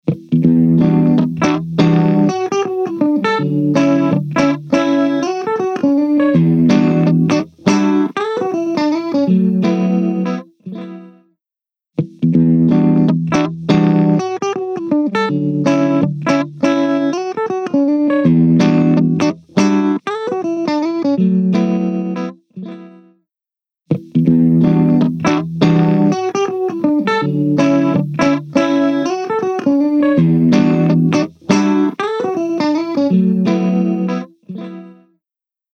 H910 Harmonizer | Electric Guitar | Preset: MicroPitched Guitars (Dual)
ドラムの厚み付け、ボーカルのダブリング、その他のユニークなエフェクト用途
H910-Dual-Harmonizer-Eventide-Guitar-Micro-Pitched-Guitars.mp3